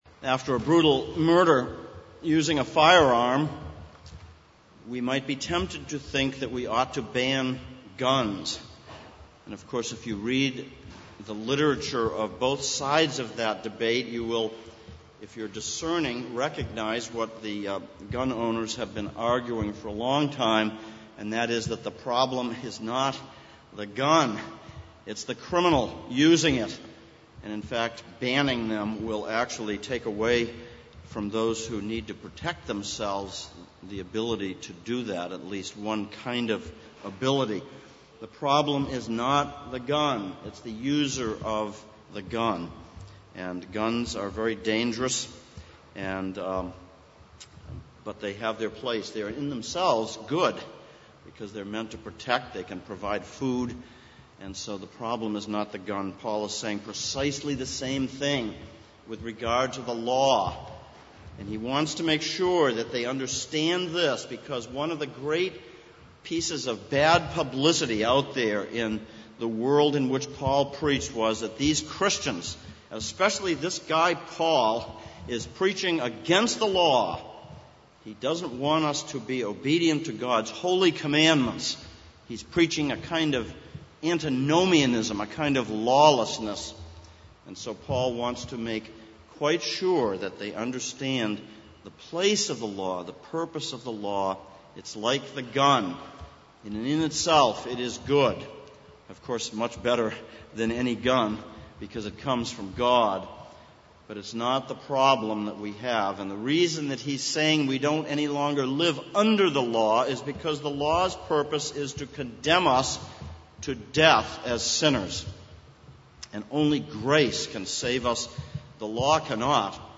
Exposition of Romans Passage: Romans 7:1-12 Service Type: Sunday Morning « 01.